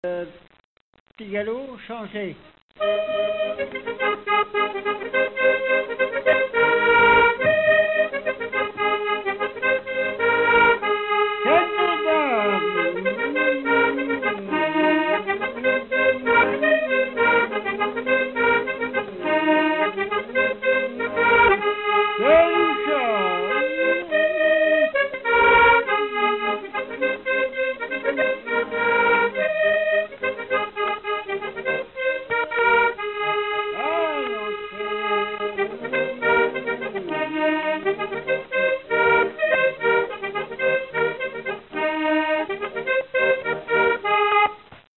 instrumental
danse : quadrille : petit galop
Pièce musicale inédite